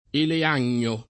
ele#n’n’o] s. m. (bot.) — anche olivagno [oliv#n’n’o], con traduzione del 1° componente del nome dal greco (ele-) al latino (oliv-), in armonia col vecchio sinonimo olivo di Boemia — raro olivagnolo [oliv#n’n’olo]; sim. il cogn. Olivagnoli — solo eleagnacee [elean’n’e-e] s. f. pl., come nome dell’intera famiglia di piante